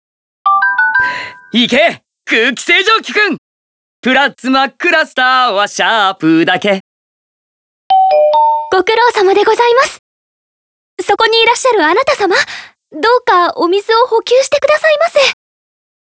大家電開発秘話【ボイス編】
はい、ランダムで、複数パターンから１つを聞くことができます。